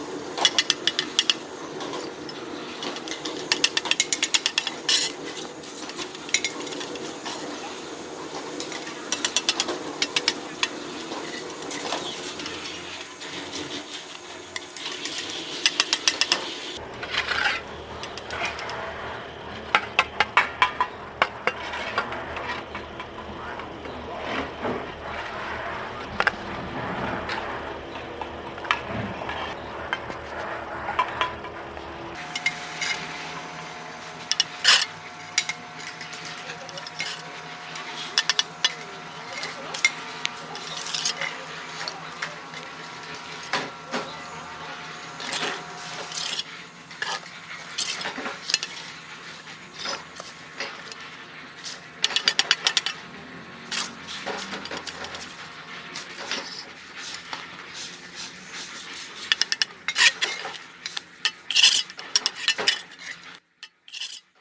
Tiếng dùng Bay, Bê, Xây tường, Xây gạch… của thợ xây
Tiếng dùng Bay, Bê, trát vữa… của thợ xây, thợ hồ Tiếng Phụ Hồ, trộn Vữa, trộn Cát, Sạn Bê tông… bằng Tay
Thể loại: Tiếng động
Description: Âm thanh đặc trưng trong công việc của thợ xây, thợ hồ, tiếng xèo xèo khi trát vữa lên tường, tiếng soạt soạt, xoạt xoạt của bay miết vữa, xen lẫn với tiếng cạch cạch, bộp bộp khi gạch được xếp và gõ ngay ngắn vào hàng. Tiếng bê hồ, xúc vữa, vỗ tường, tiếng cạo cạo của bay thép chạm mặt gạch khô...
tieng-dung-bay-be-xay-tuong-xay-gach-cua-tho-xay-www_tiengdong_com.mp3